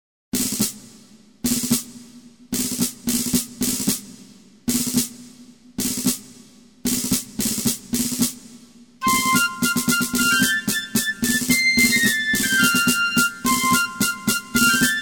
Patriotic/Military